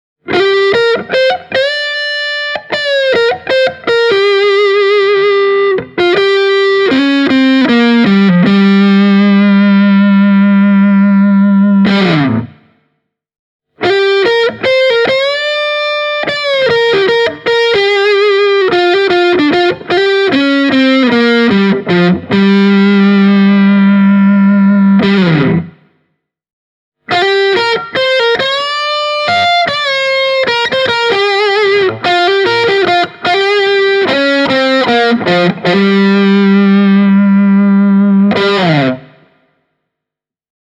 Tässä muutama esimerkki GA-112:n soundista ilman ulkoisia efektilaiteita:
Kasuga puoliakustinen – soolosoundi
kasuga-semi-e28093-lead-sound.mp3